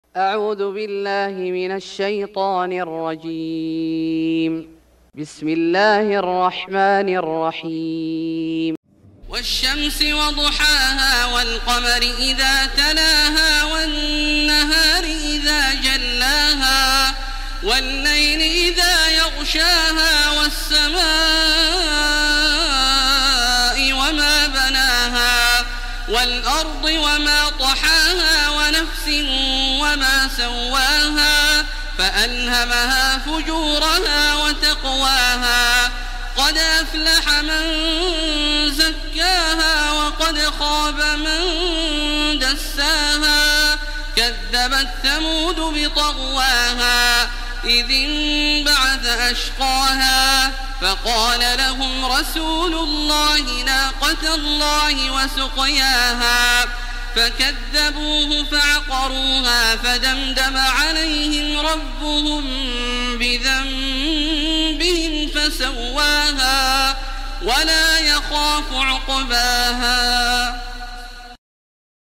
سورة الشمس Surat Ash-Shams > مصحف الشيخ عبدالله الجهني من الحرم المكي > المصحف - تلاوات الحرمين